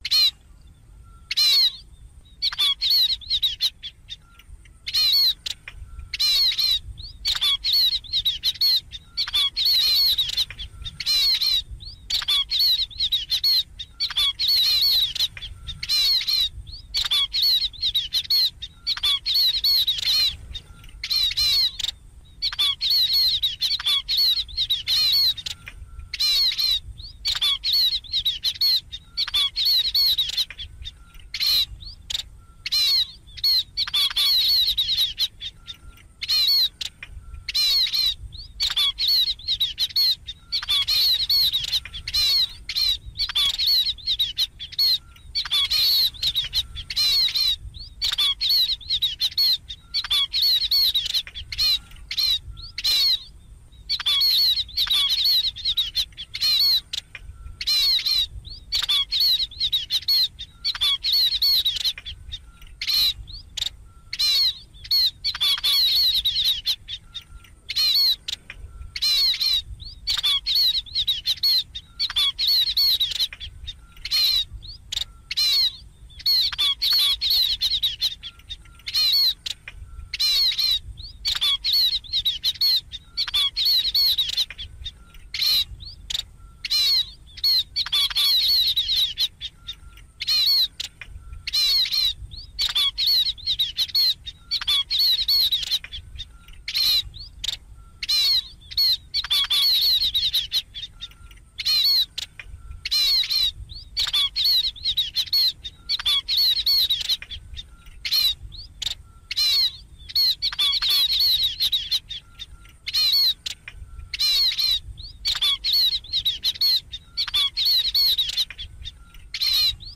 Tiếng Ri Sắc Ô
Download tiếng ri sắc ô mp3 không có tạp âm cho điện thoại, máy tính.
Tiếng kêu của Ri Sắc Ô thường là những chuỗi âm thanh "rít... rít..." nhanh, nhỏ nhưng có độ đanh và sức xuyên thấu cao.
• Âm thanh rộn ràng, vui tai: Tiếng Ri Sắc Ô thường xuất hiện theo bầy đàn, tạo nên một bầu không khí náo nhiệt, ríu rít.
• Nhịp điệu dồn dập tự nhiên: Với các quãng âm ngắn và liên tục, tiếng Ri Sắc Ô tạo ra một nhịp điệu sinh động, rất phù hợp để làm âm thanh nền cho các đoạn chuyển cảnh nhanh trong video.
• Bản thu độ nét cao: File âm thanh đã được xử lý lọc sạch các tạp âm như tiếng gió rít hay tiếng xe cộ, giữ lại trọn vẹn dải âm thanh trong trẻo nhất của loài chim này.